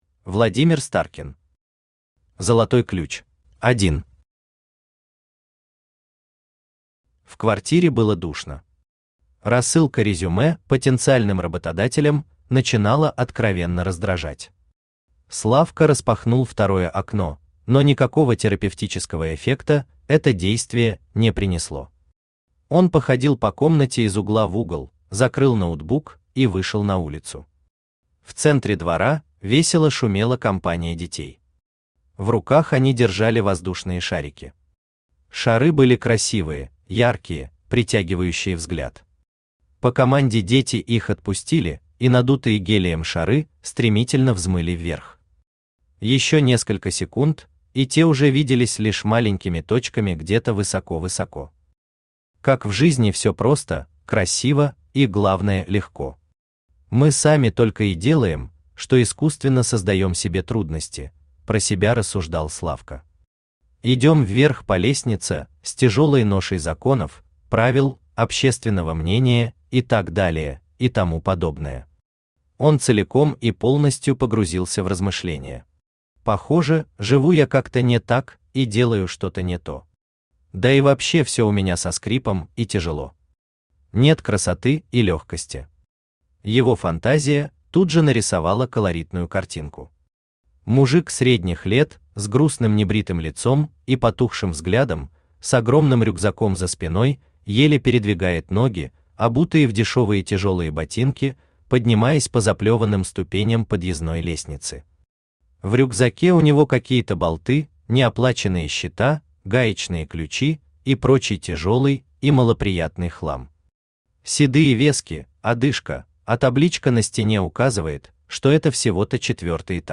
Aудиокнига Золотой ключ Автор Владимир Старкин Читает аудиокнигу Авточтец ЛитРес.